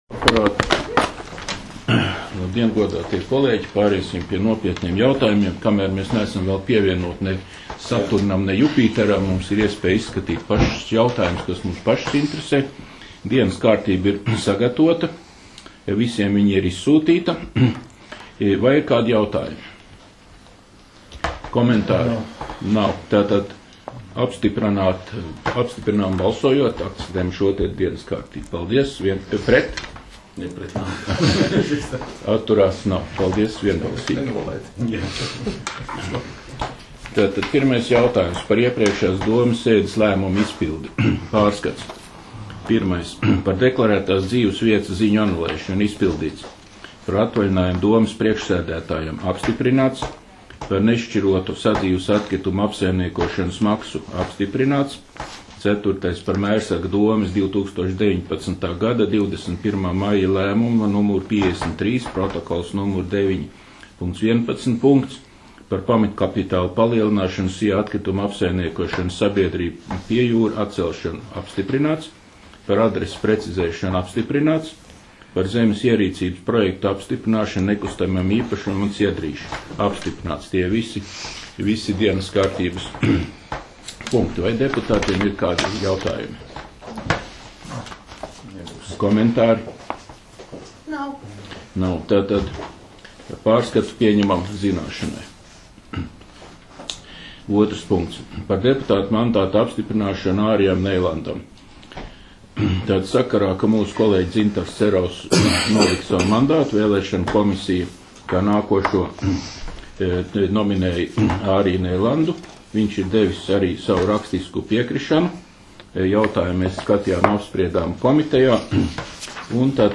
Mērsraga novada domes sēde 16.07.2019.